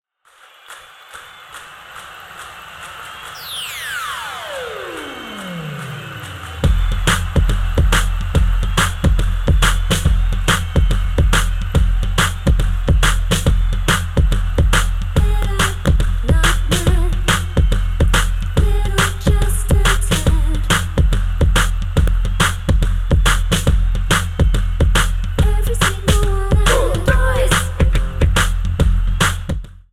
This is an instrumental backing track cover.
• Key – Fm
• With Backing Vocals
• No Fade